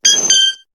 Cri de Mélodelfe dans Pokémon HOME.